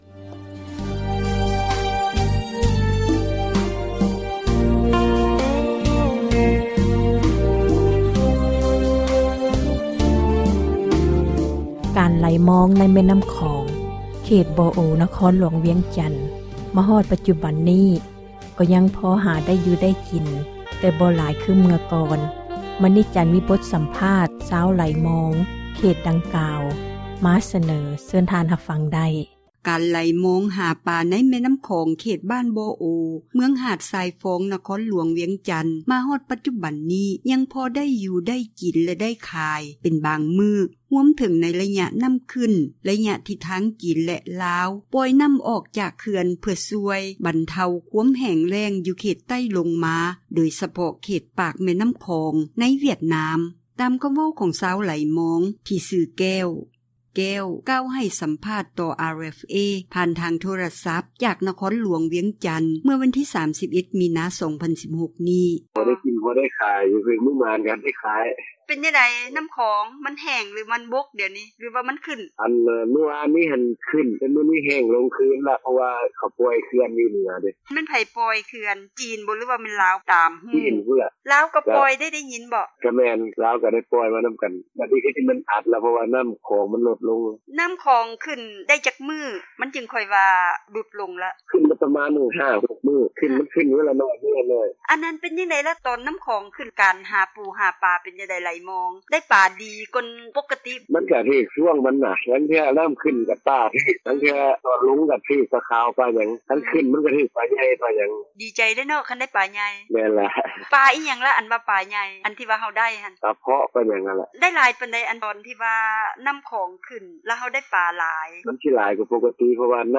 ສໍາພາດຄົນຫາປາໃນລໍາ ນໍ້າຂອງ